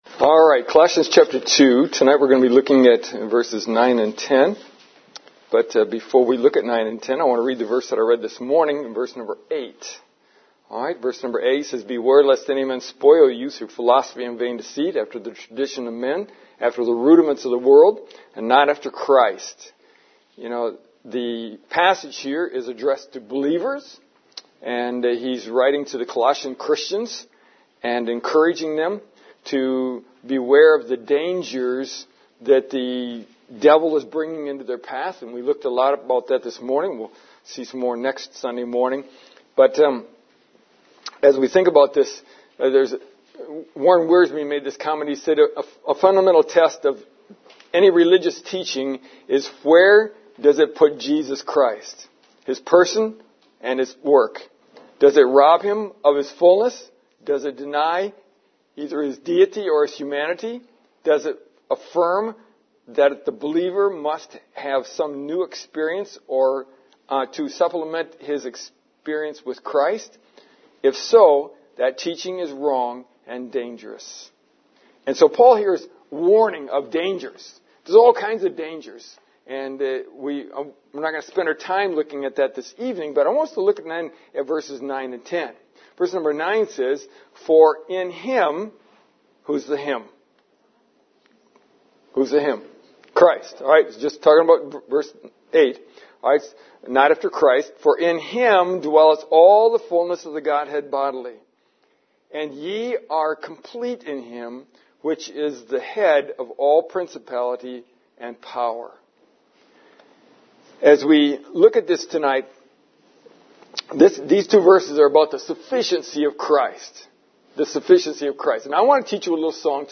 I will sing it, and then I want you to join me.